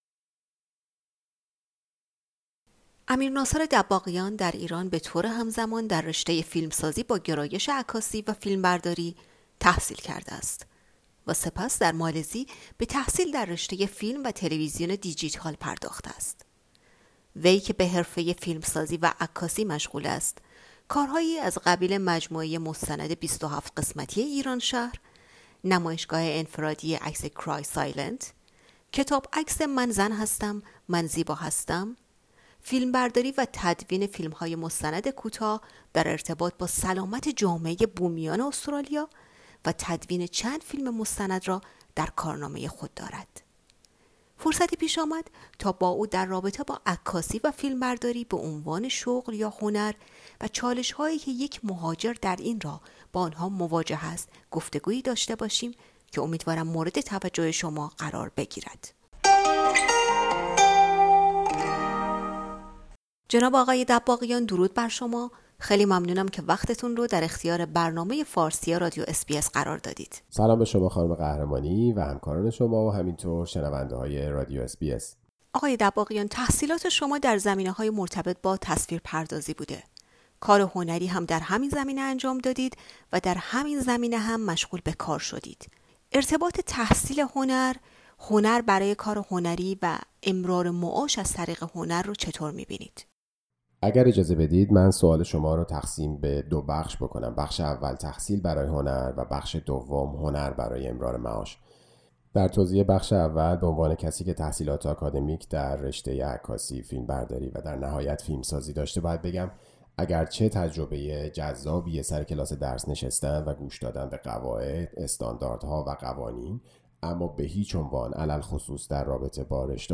فرصتی پیش آمد تا با او در رابطه با عکاسی و فیلمبرداری به عنوان شغل یا هنر و چالش هایی که یک مهاجر در این راه با آنها مواجه است گفتگو کنیم.